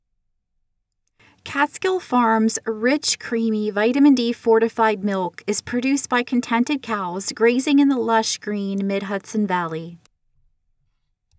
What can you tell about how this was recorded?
Both of your last two tests (982.73 KiB) & (967.73 KiB) sound very boxy to me.